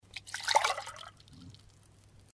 Звуки стакана
Быстро хлюпнули воды в стакан